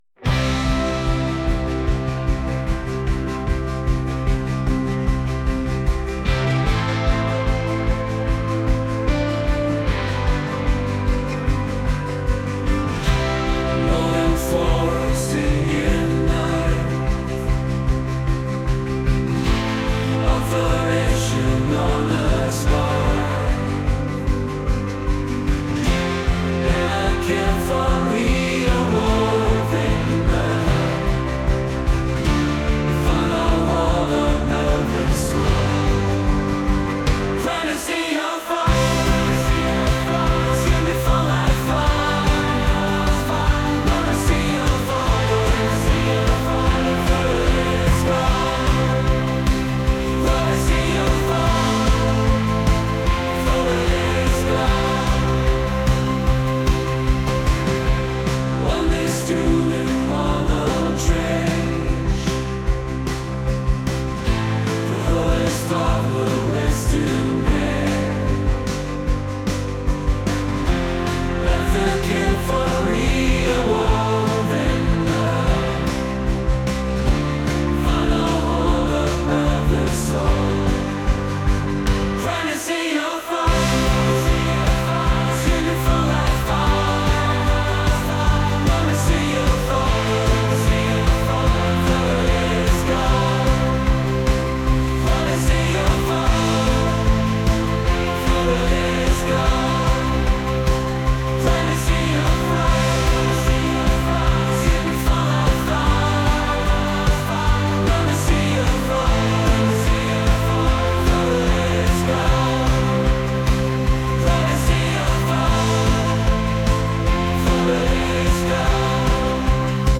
indie | rock | acoustic